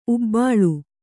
♪ ubbāḷu